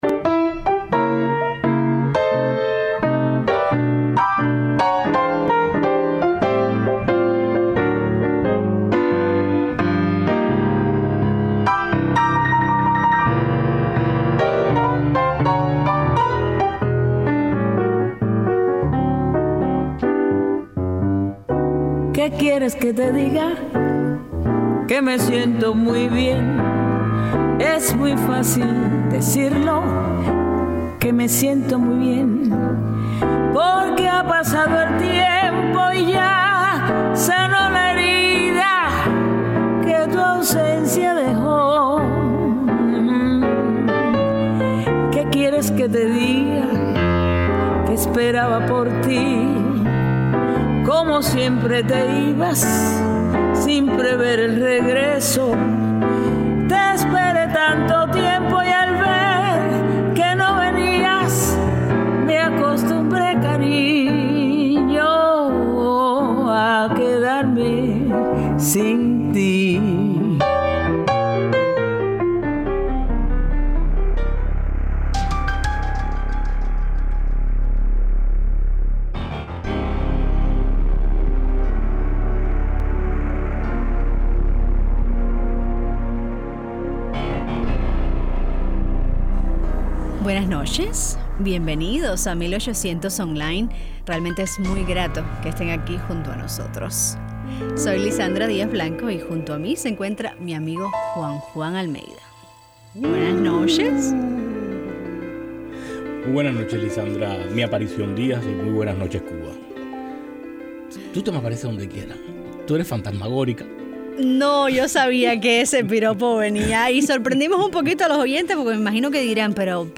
El cazafantasmas mexicano Carlos Trejo conversa con nosotros sobre los casos de actividad paranormal más interesantes en los que ha participado, el uso de animales para detectar estos entes, las características de los lugares que están habitados por espíritus y el modo de ayudarlos a seguir su camino hacia la luz.